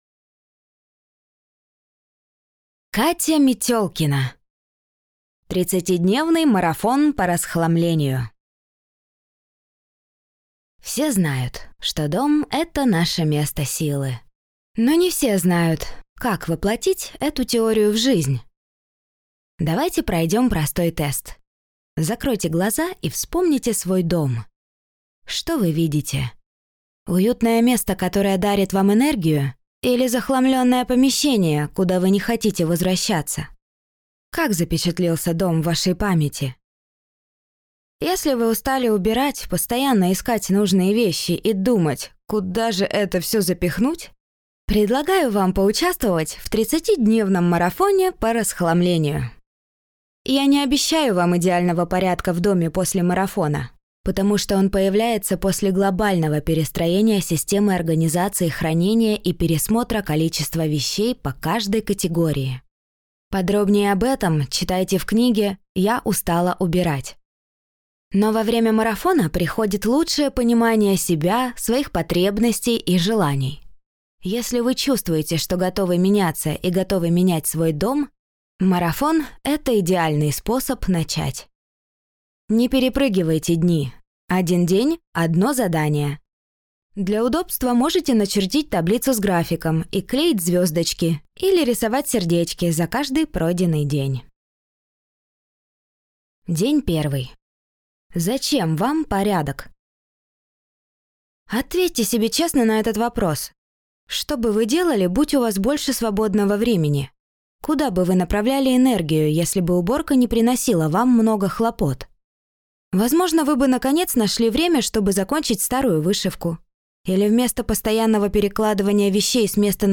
Аудиокнига 30-ти дневный марафон по расхламлению | Библиотека аудиокниг